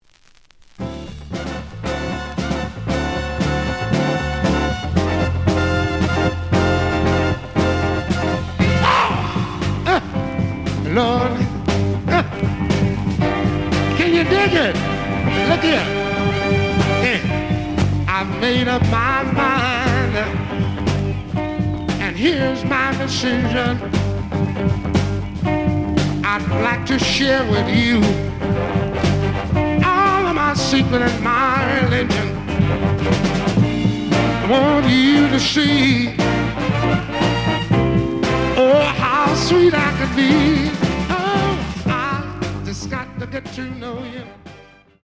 The audio sample is recorded from the actual item.
●Genre: Blues
Slight edge warp. But doesn't affect playing. Plays good.